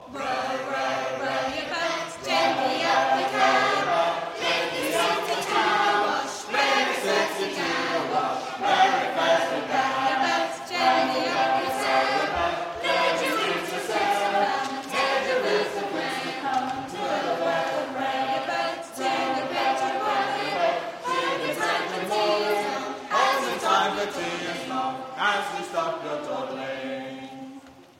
Singing History Concert 2016: To the Wash 3